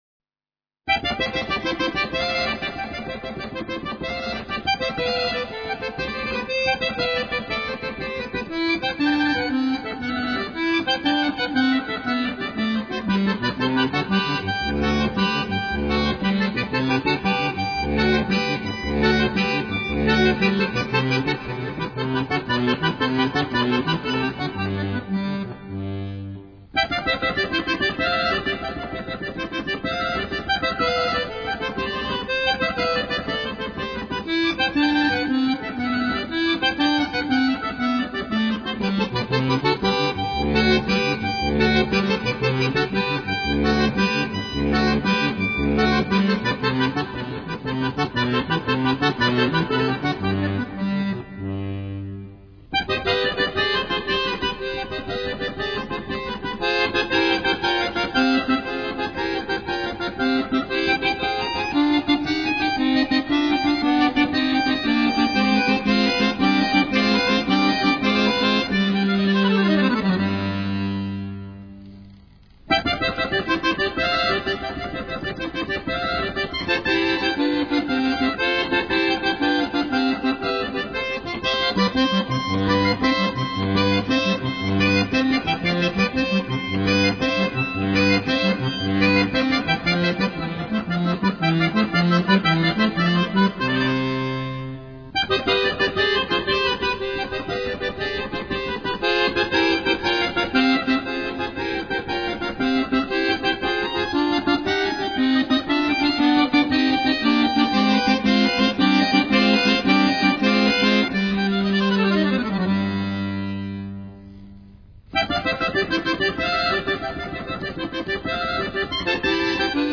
Fisarmonica